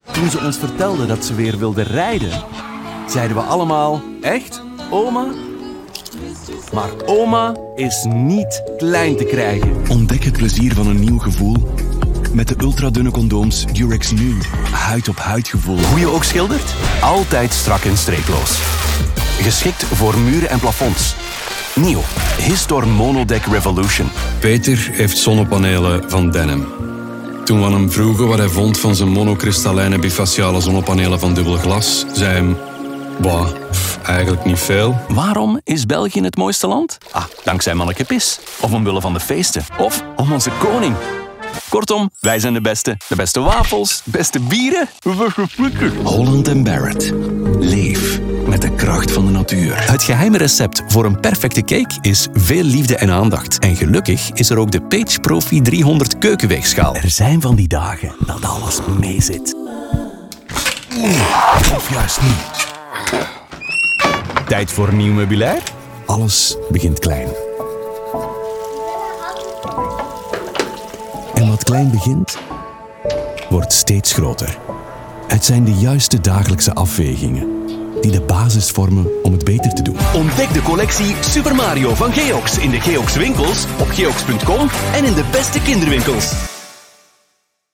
Diep, Toegankelijk, Vertrouwd, Natuurlijk, Warm
Commercieel